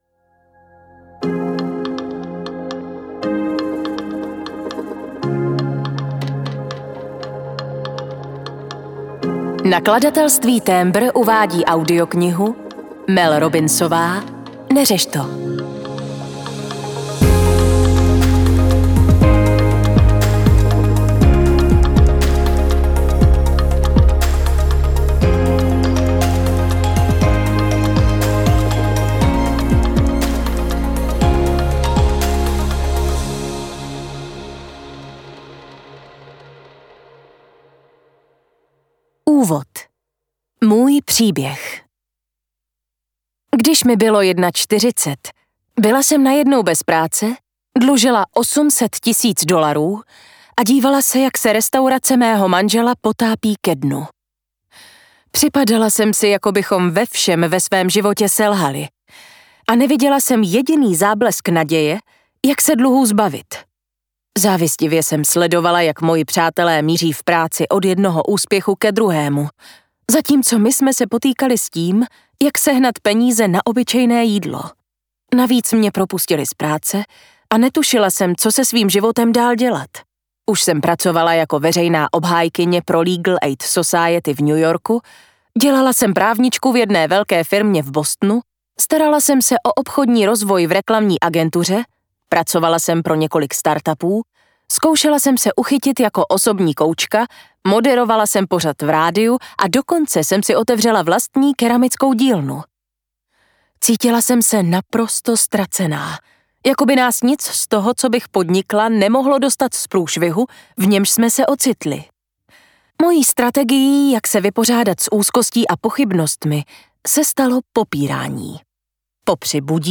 Neřeš to audiokniha
Ukázka z knihy